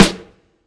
Snares
Snrhfj_snr.wav